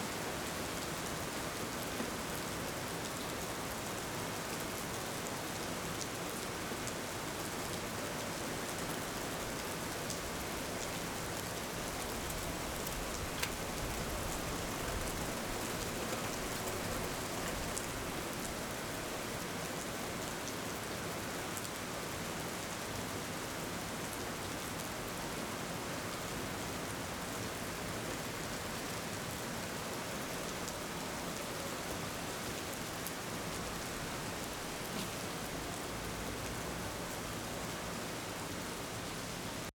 Index of /audio/samples/SFX/IRL Recorded/Rain - Thunder/
Rain 2.wav